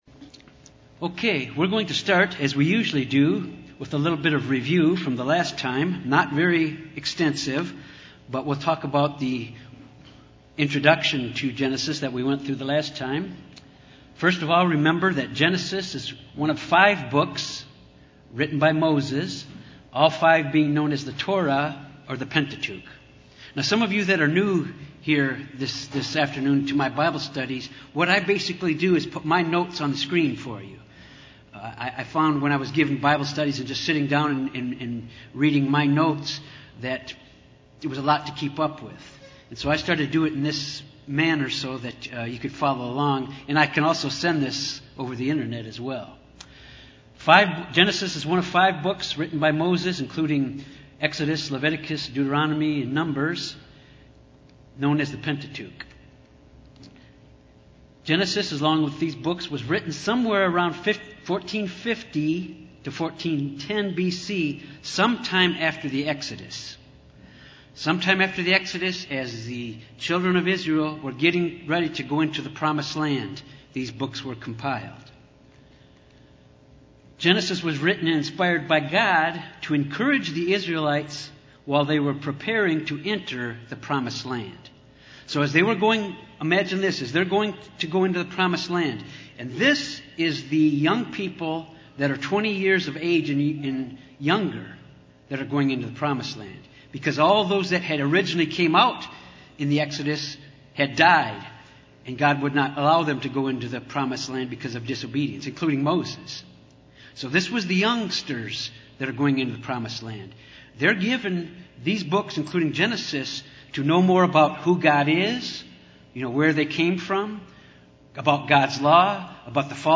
This Bible Study focuses on Genesis 1-2 dealing with the recreation of the Earth and plant and animal life.
Given in Little Rock, AR
UCG Sermon Studying the bible?